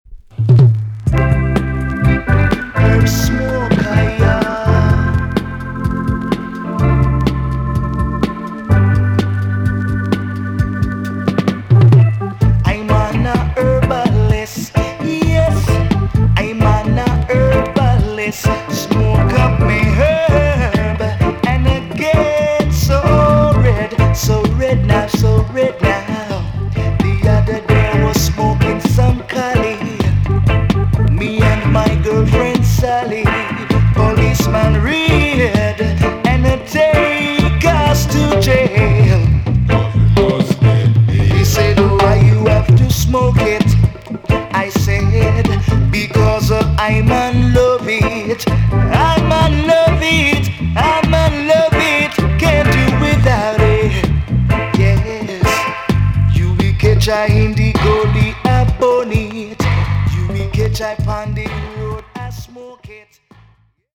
EX-~VG+ 少し軽いチリノイズが入りますがキレイです。
B.SIDEのROOTS TUNEもNICE!!